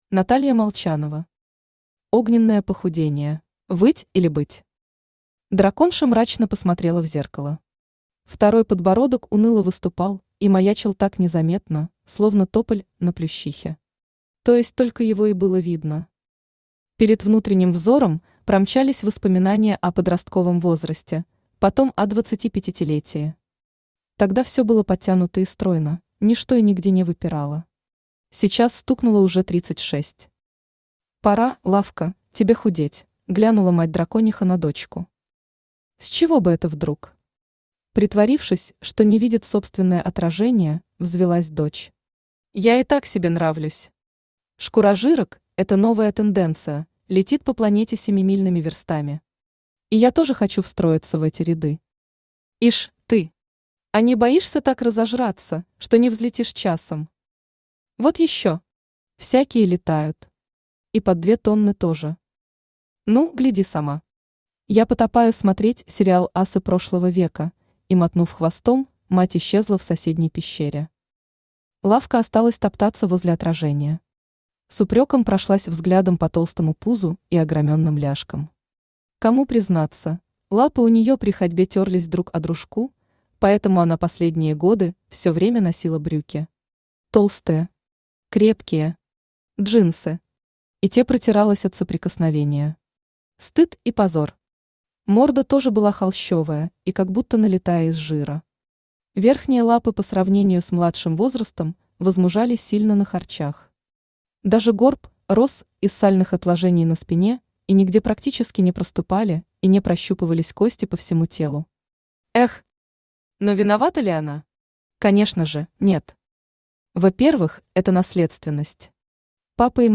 Аудиокнига Огненное похудение!
Aудиокнига Огненное похудение! Автор Наталья Геннадьевна Молчанова Читает аудиокнигу Авточтец ЛитРес.